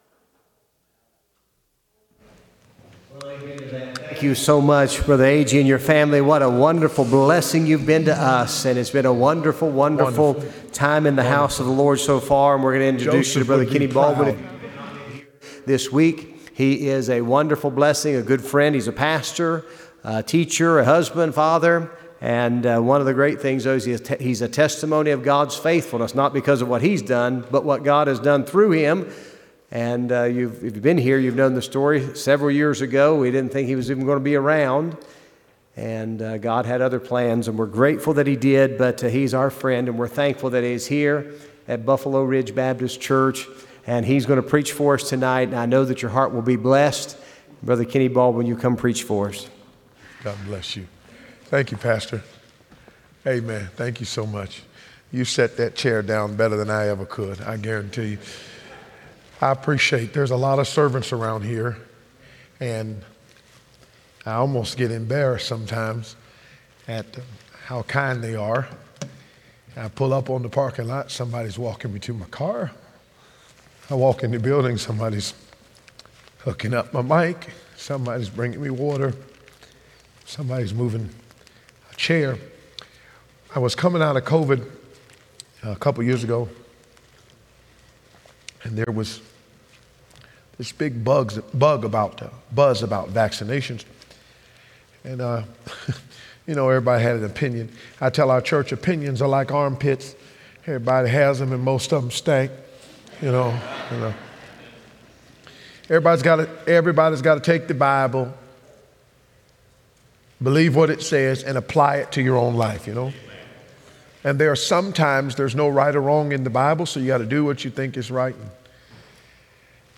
Revival Service